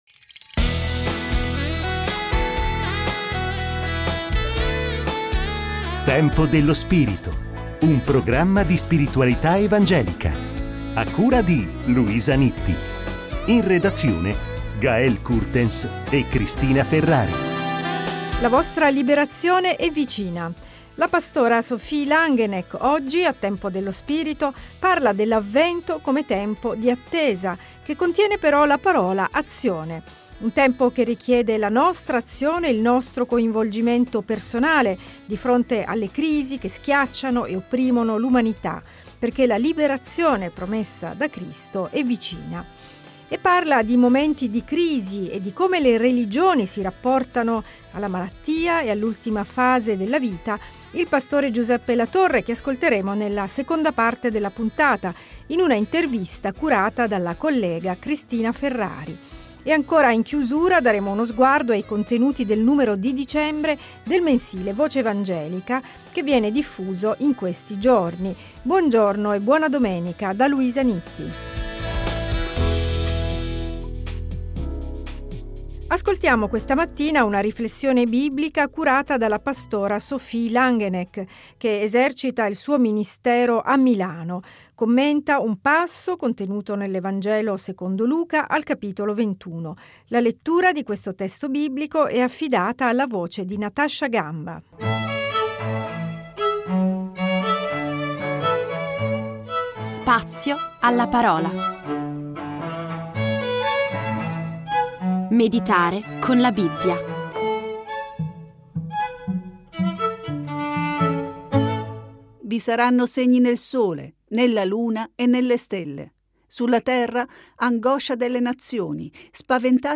- In chiusura, uno sguardo ai contenuti del numero di dicembre del mensile “Voce Evangelica”, edito dalla Conferenza delle Chiese evangeliche di lingua italiana in Svizzera. Scopri la serie Tempo dello spirito Settimanale di spiritualità evangelica.